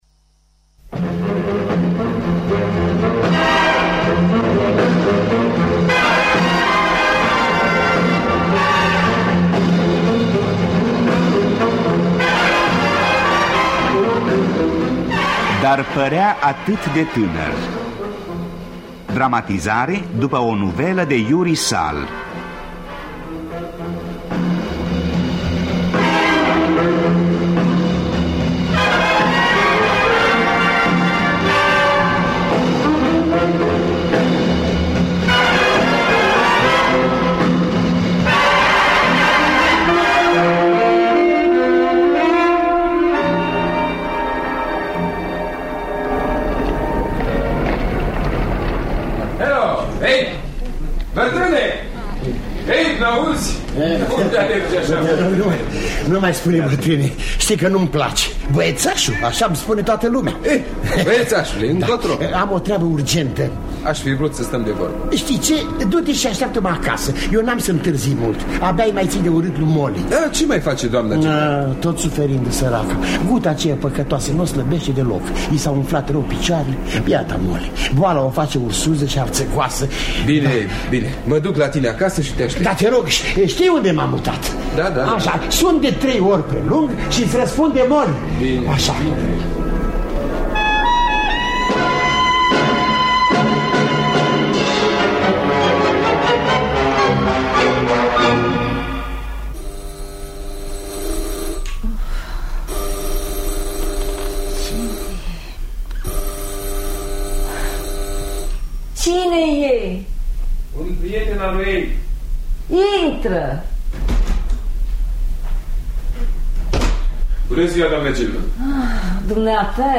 Dar părea atât de tânăr de Yuri Suhl – Teatru Radiofonic Online